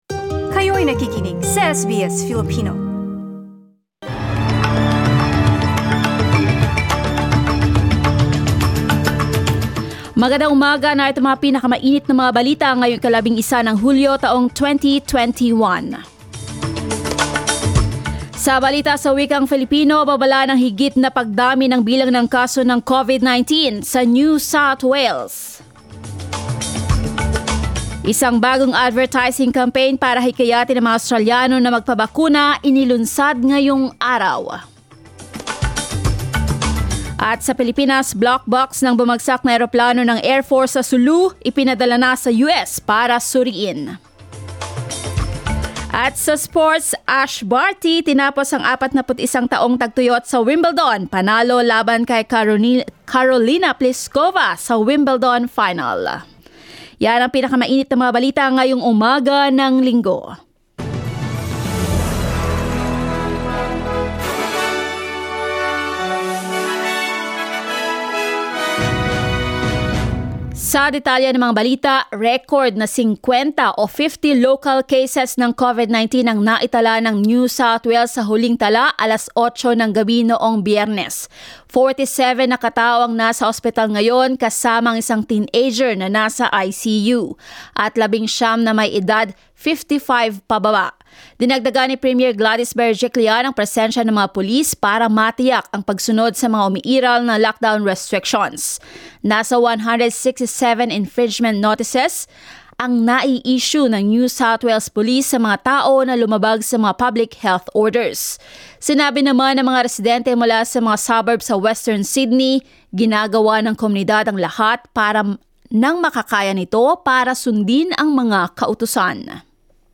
Mga balita ngayong ika-11 ng Hulyo